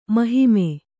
♪ mahime